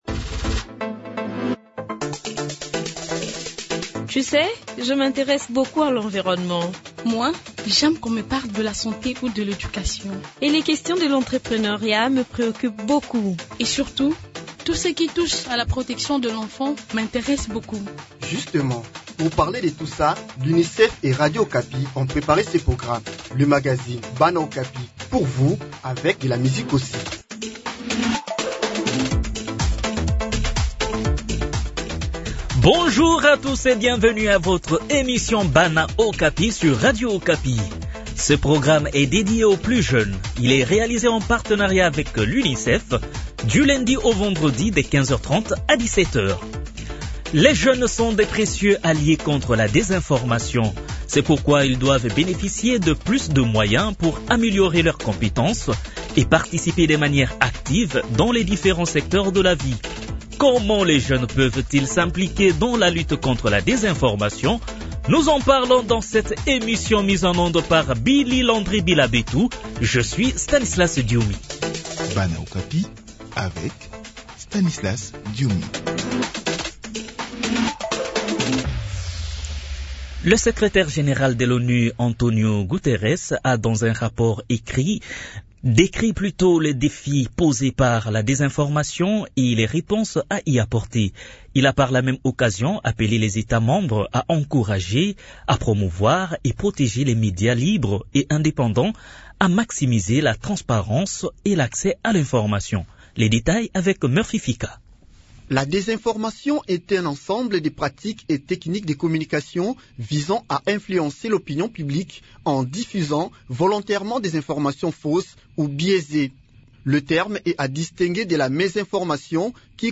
Des jeunes, des experts ont donné leurs avis à ce sujet dans cette mission.